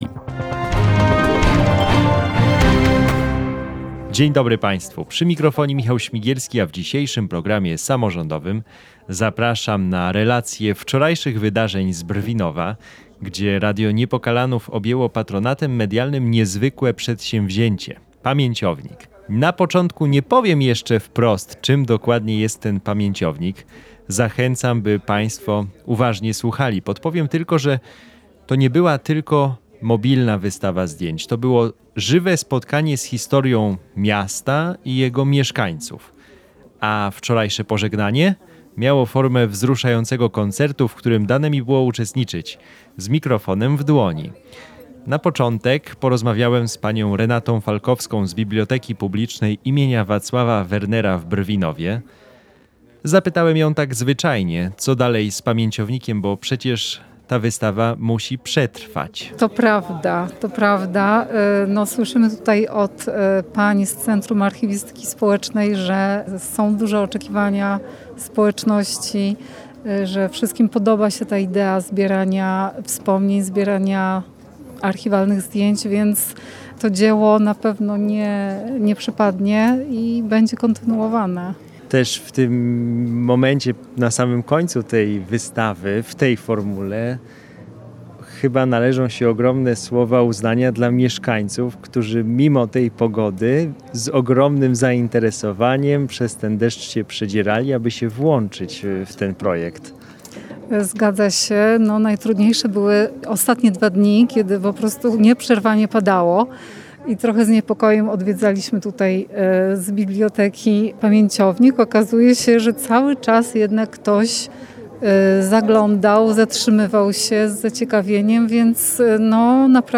Audycja w radiu Niepokalanów 102,7 FM Pobierz plik